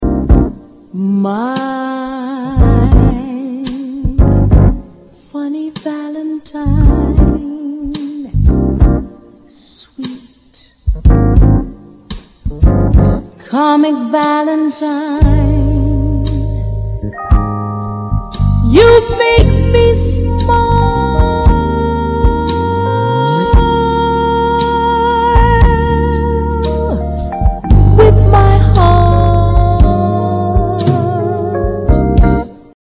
J'adore ces gros sons de basses avec le Rhodes.....encore :)